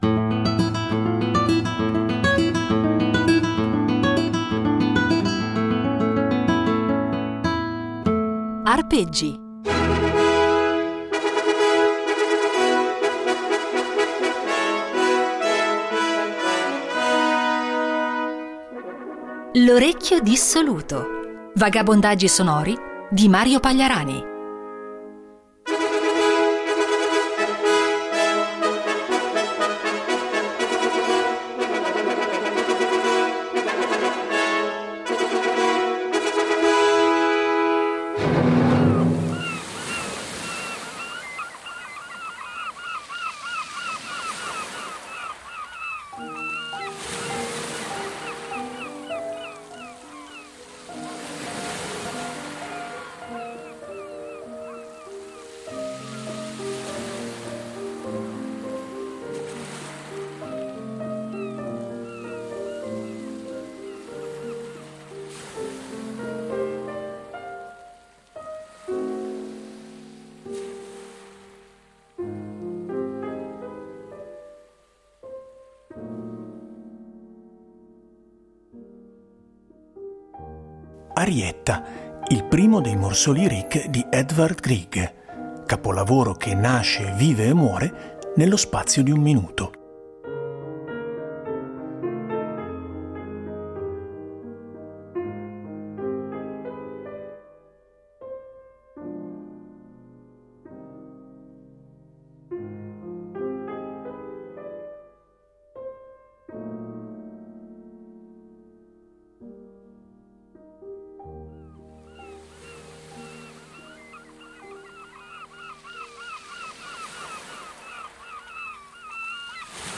Un invito a perdersi tra suoni, rumori, e ascolti immaginifici che si rincorrono senza meta.